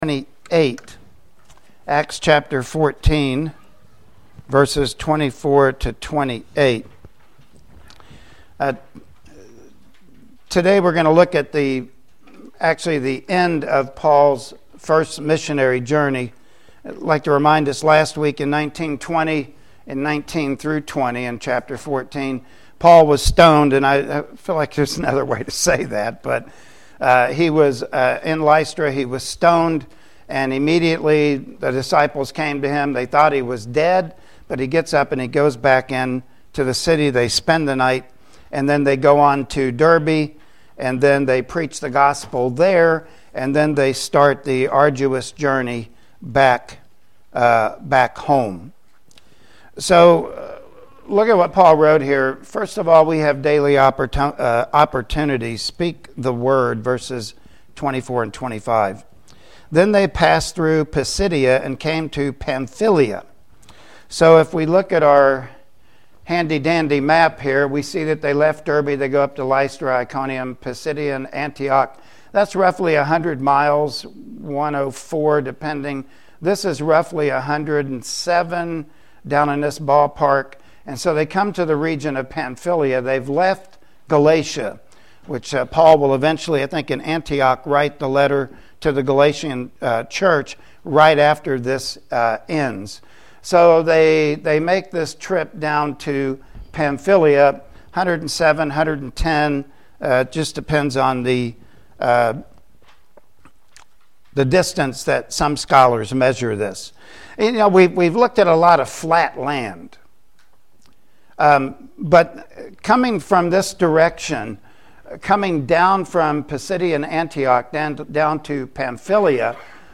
Acts 14 & 15 Passage: Acts 14:24-28 Service Type: Sunday Morning Worship Service Topics